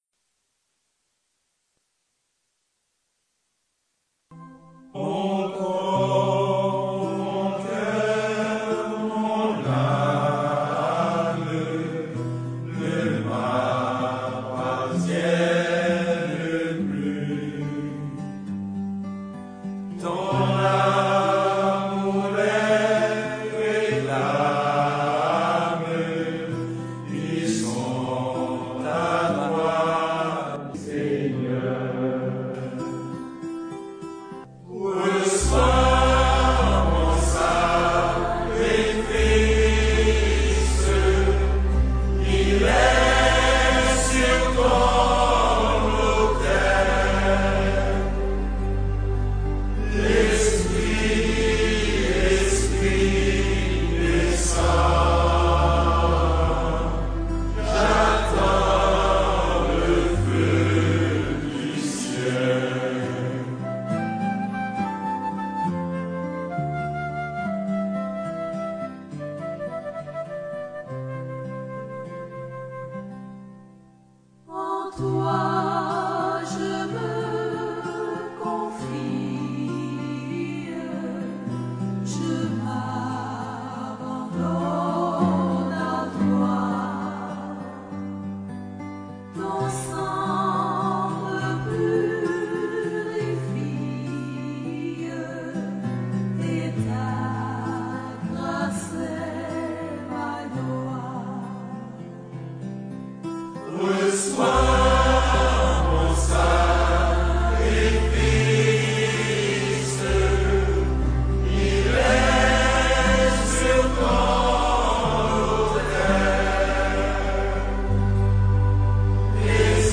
2022 CHANTS MYSTIQUES audio closed https